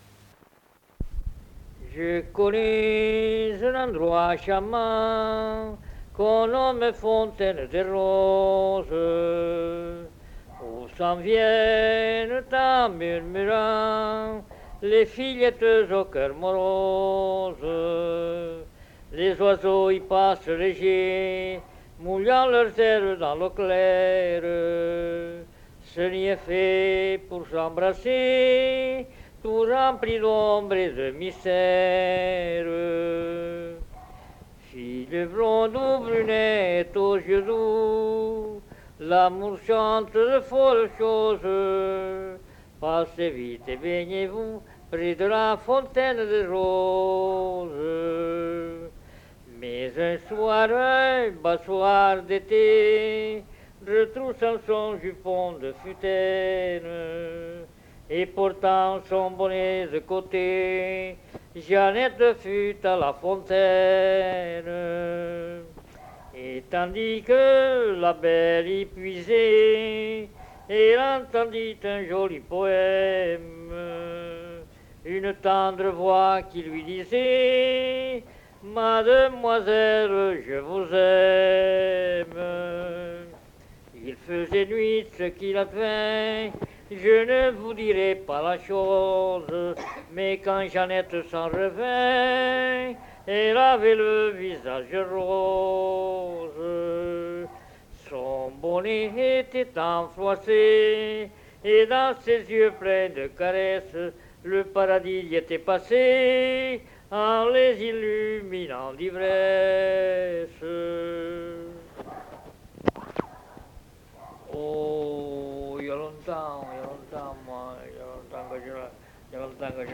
Aire culturelle : Cabardès
Lieu : Mas-Cabardès
Genre : chant
Effectif : 1
Type de voix : voix d'homme
Production du son : chanté